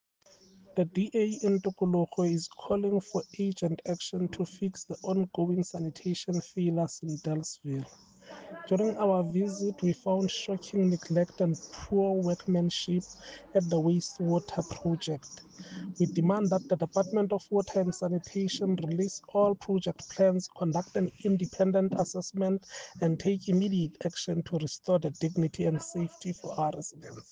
Sesotho soundbites by Cllr Hismajesty Maqhubu and Afrikaans soundbite by David Mc Kay MPL.